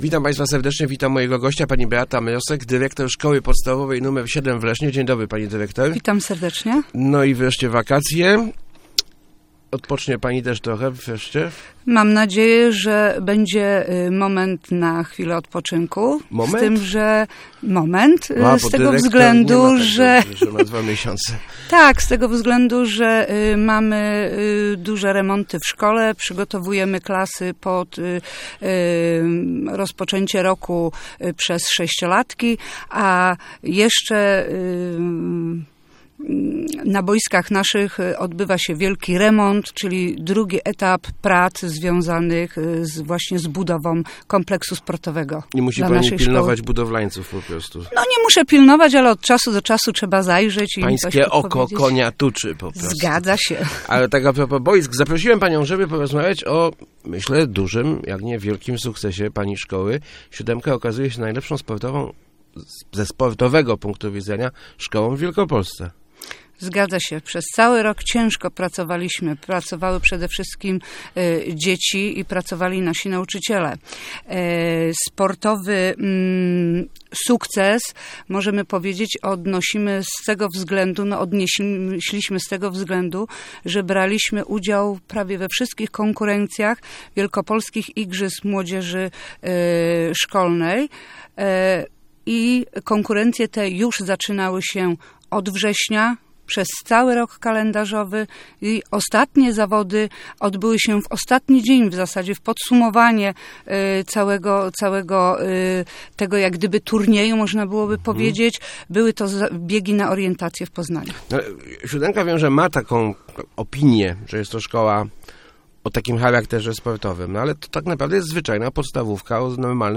Start arrow Rozmowy Elki arrow Najbardziej sportowa szkoła